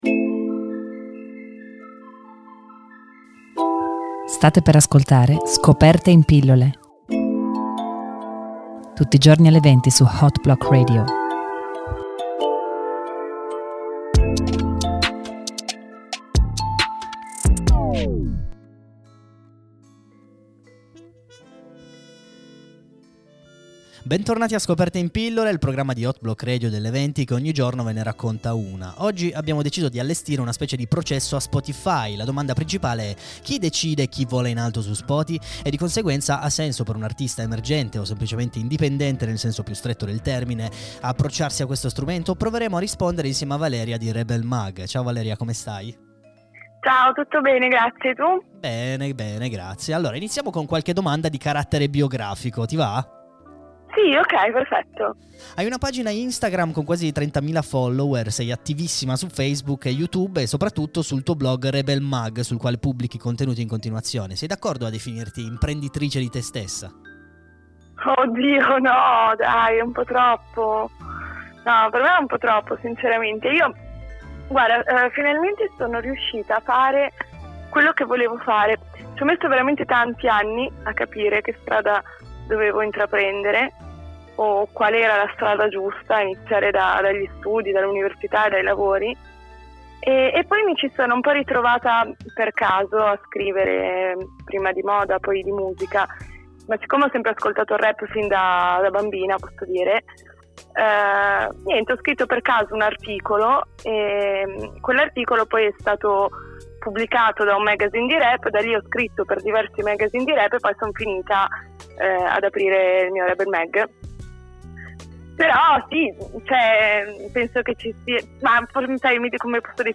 Una chiacchierata sui segreti di Spotify